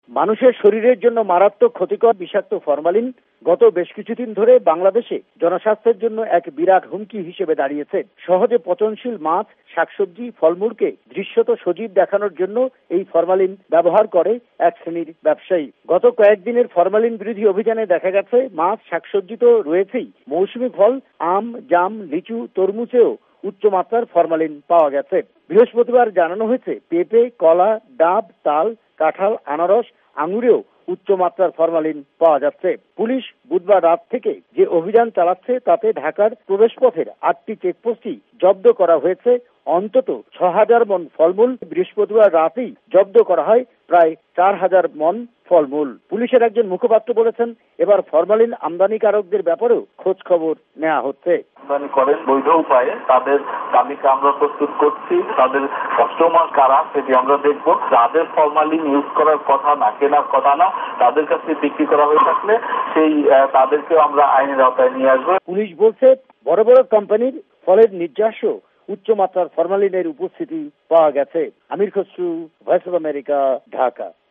ভয়েস অব আমেরিকার ঢাকা সংবাদদাতাদের রিপোর্ট